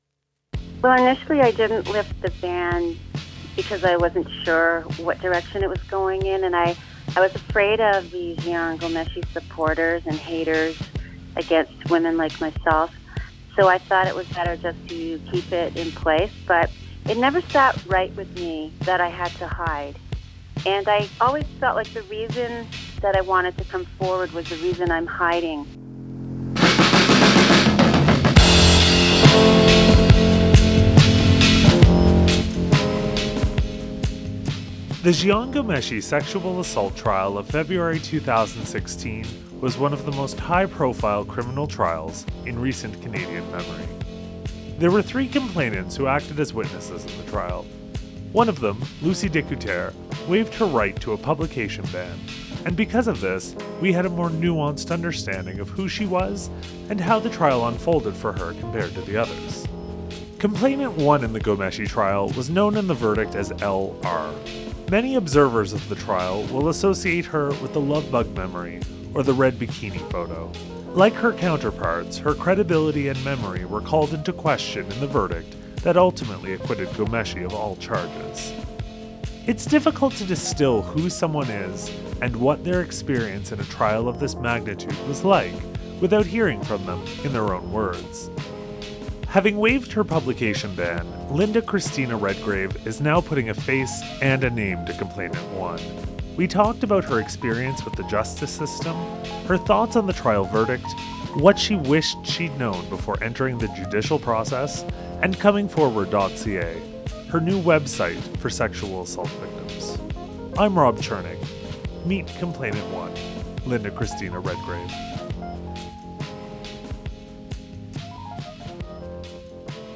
Candid interview